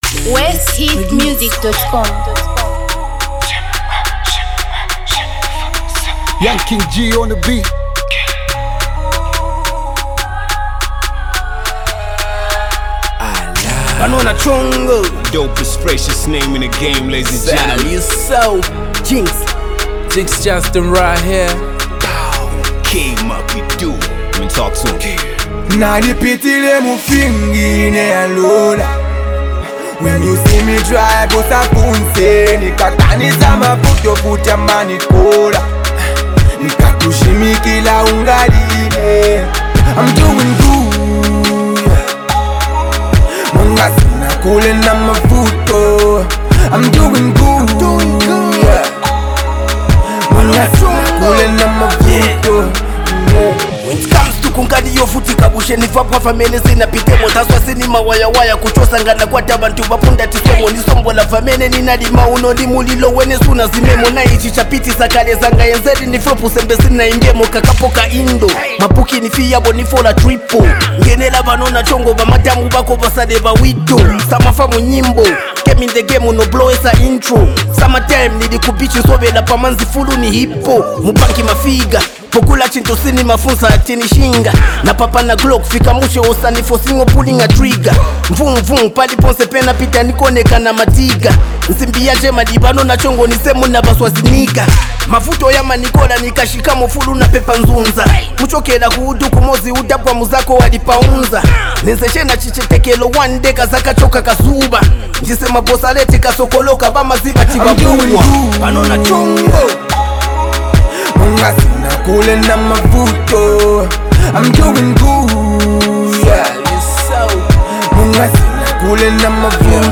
Zambia Music